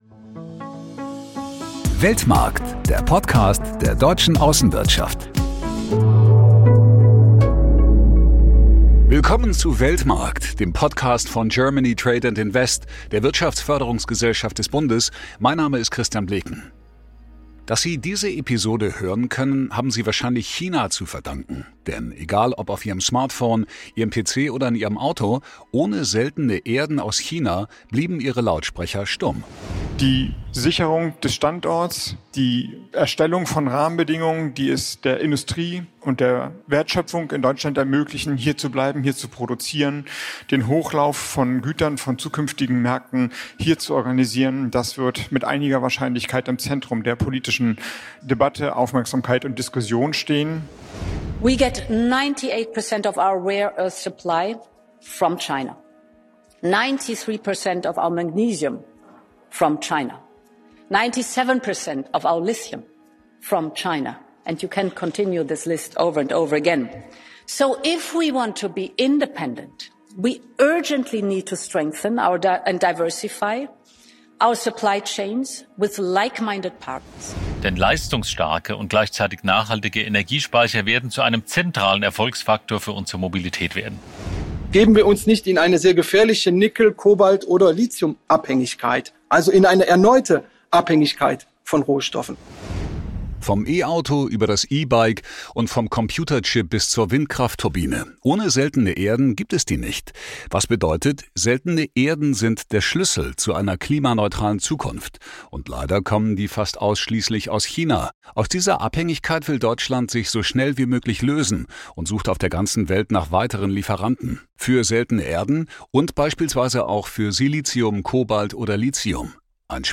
In dieser Folge sprechen wir mit Experten und Unternehmen, die an vielversprechenden Auswegen aus der Rohstoffabhängigkeit arbeiten. Wir zeigen, wieso das Monopol von China bald Vergangenheit sein könnte. Und wie sich Seltene Erden nachhaltiger abbauen lassen.